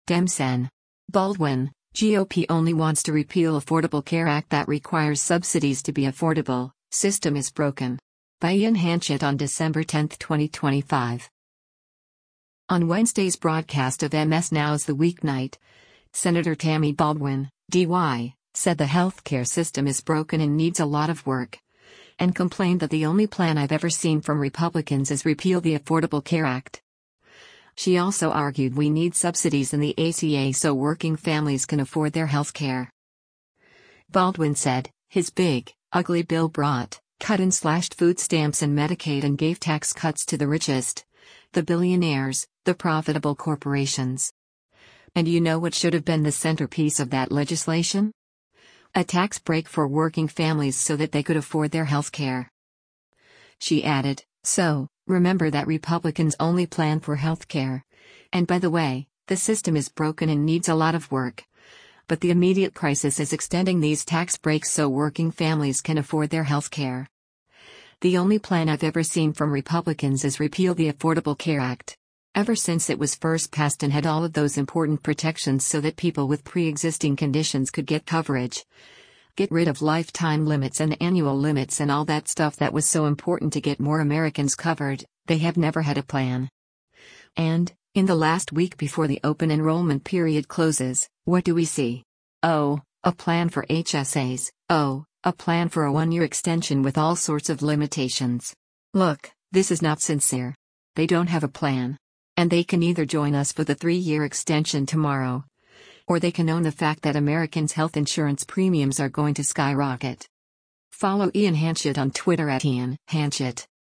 On Wednesday’s broadcast of MS NOW’s “The Weeknight,” Sen. Tammy Baldwin (D-WI) said the healthcare system “is broken and needs a lot of work,” and complained that “The only plan I’ve ever seen from Republicans is repeal the Affordable Care Act.” She also argued we need subsidies in the ACA “so working families can afford their health care.”